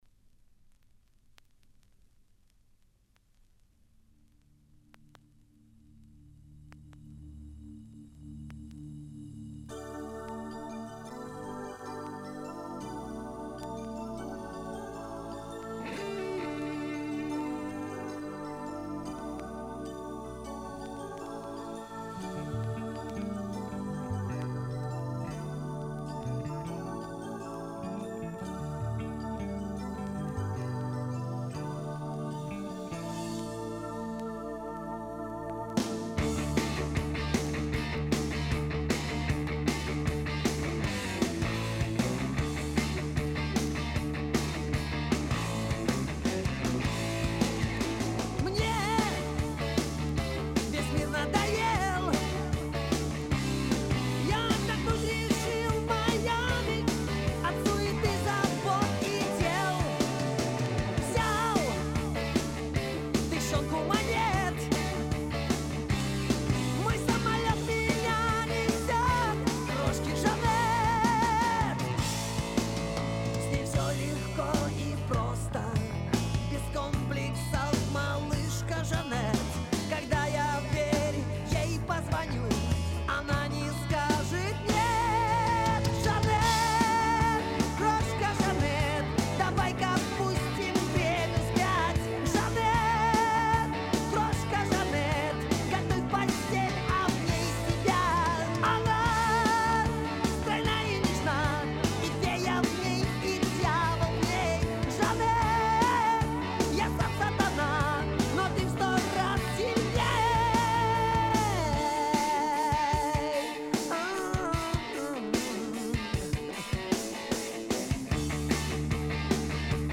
Жанр: Rock
Стиль: Soft Rock, Hard Rock